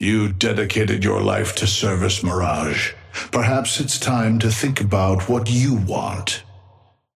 Patron_male_ally_mirage_start_02.mp3